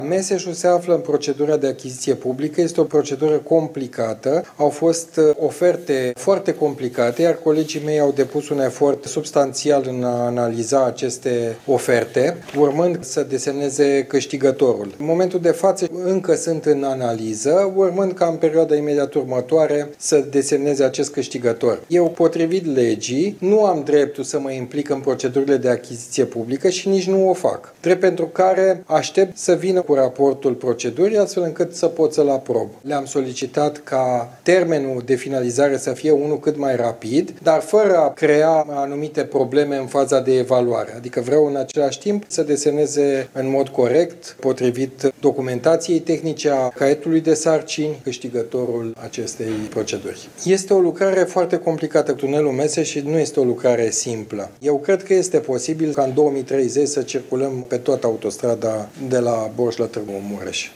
Pentru finalizarea procedurilor de licitație, directorul Companiei de Drumuri, Cristian Pistol, sugerează că nu există un orizont de timp pentru anunțarea câștigătorului: